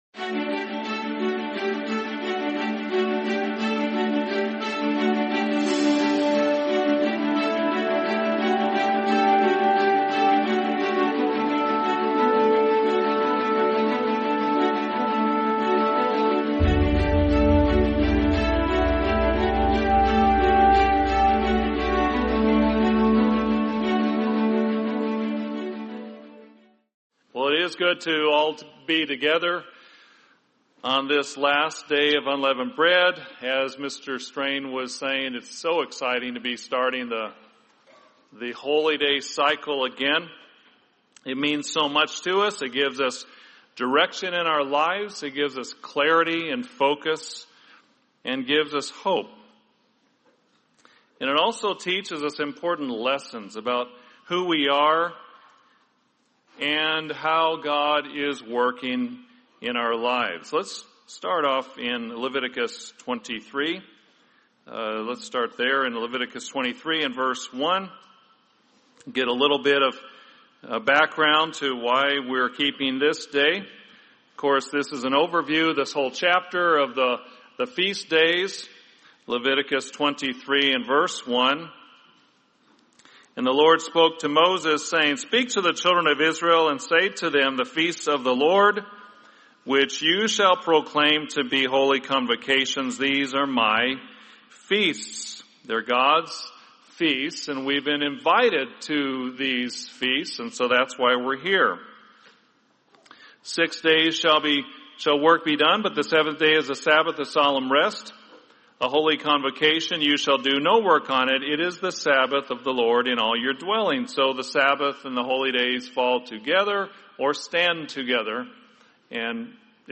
Sermon Humbling and Testing